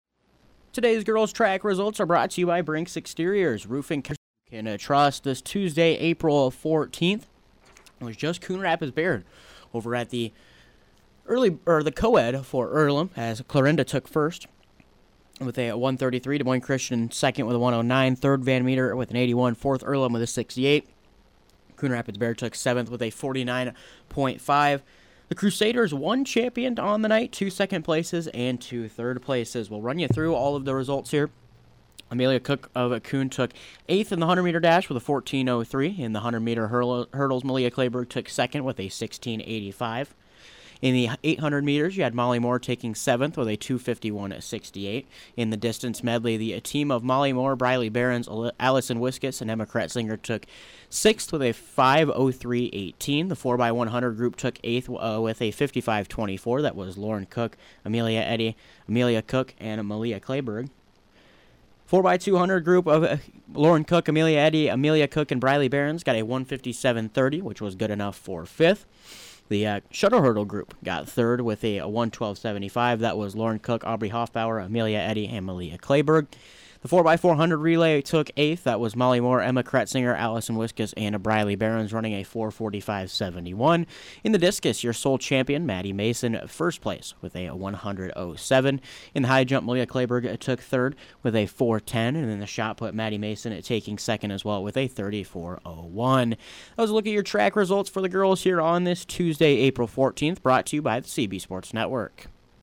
Below is an Audio Recap of girls’ track results from Tuesday, April 14th